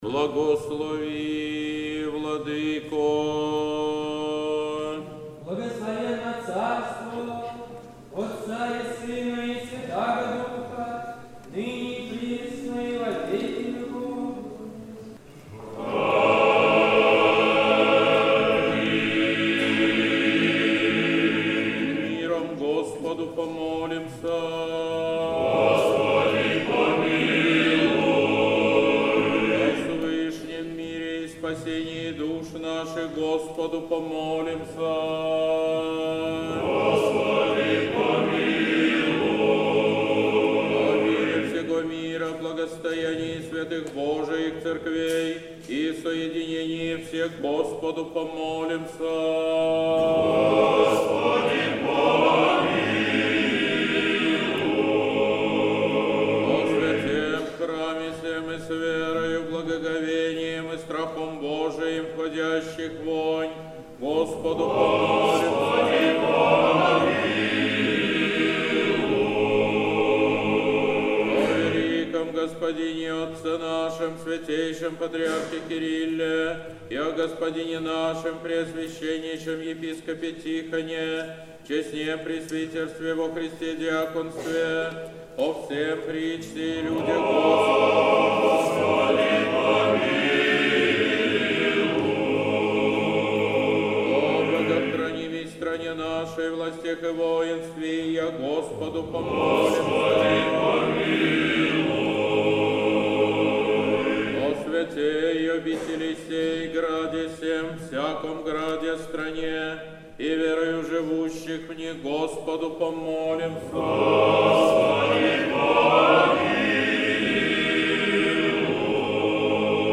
Сретенский монастырь. Божественная литургия. Хор Сретенского монастыря.